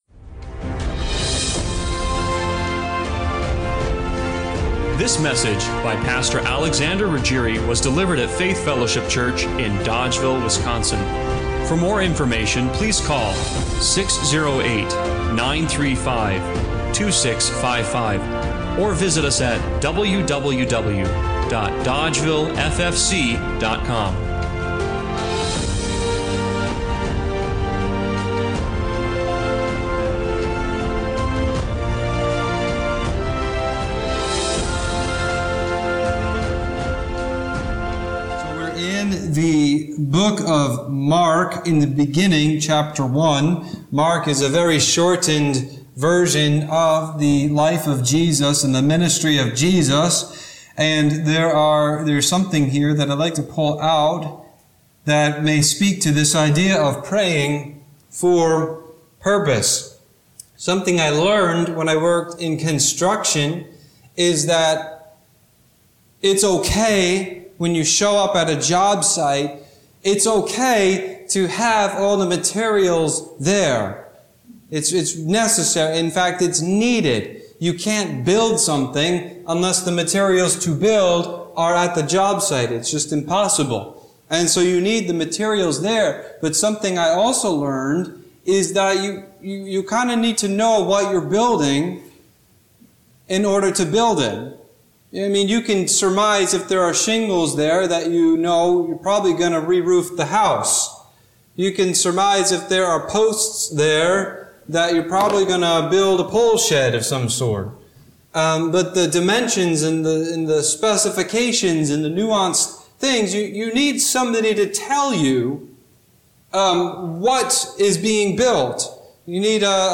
Mark 1:35-39 Service Type: Sunday Morning Worship What if you had all the pieces you needed